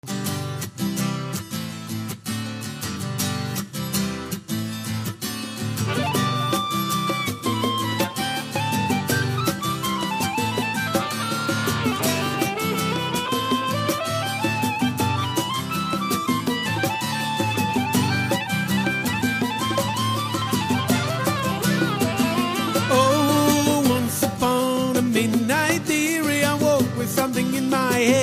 privilégiant les instruments acoustiques.